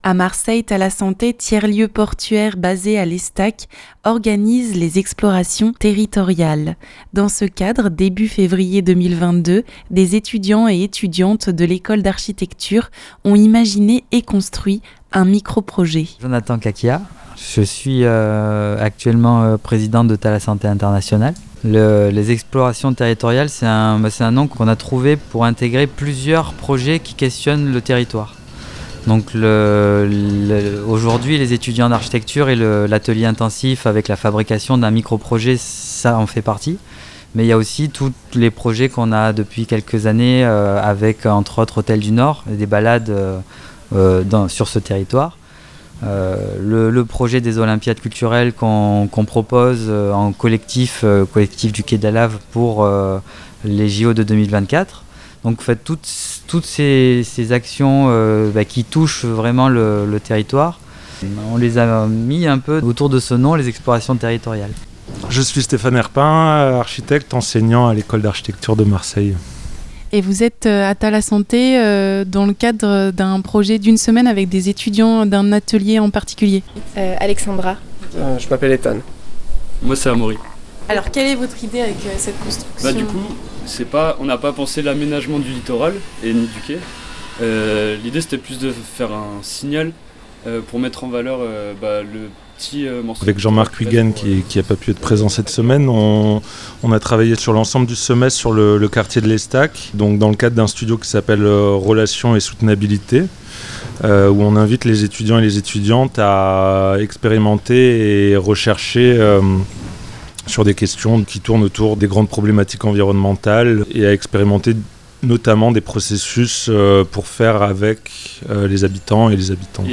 architecte et enseignant à l'école d'architecture de Marseille et les étudiant.e.s présentent cette démarche dans ce reportage in situ.